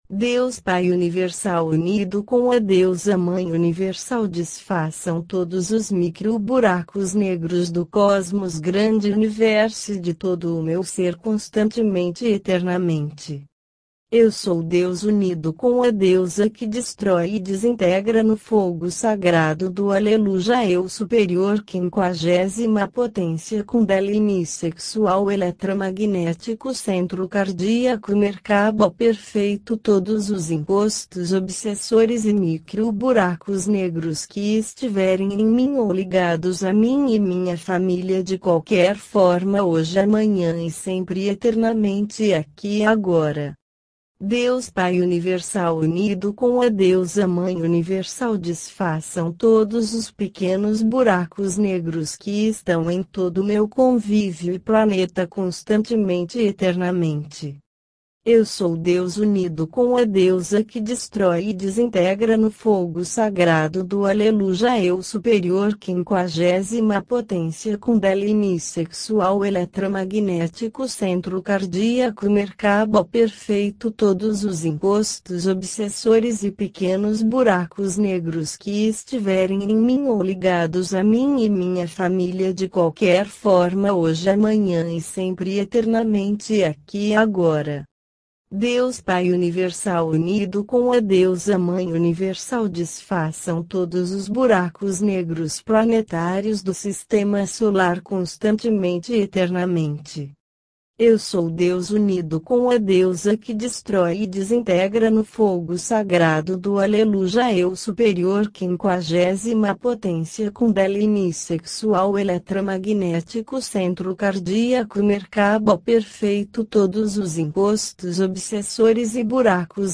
ORAÇÃO EM MP3